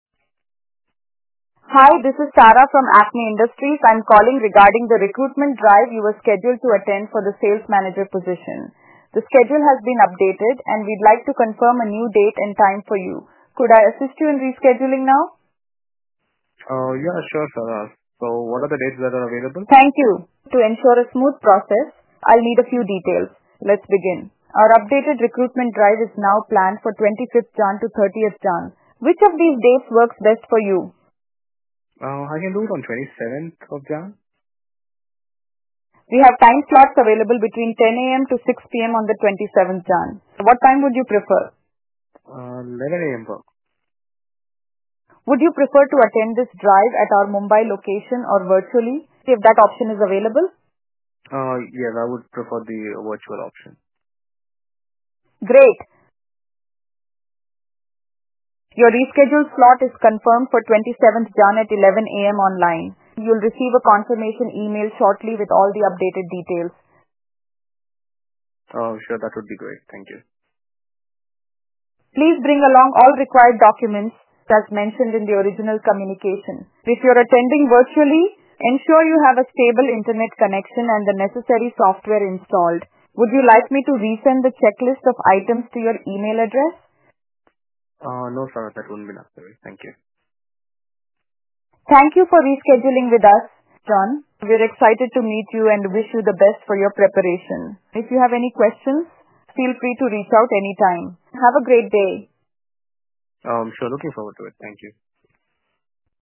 • Allows candidates to reschedule in seconds with a voice command.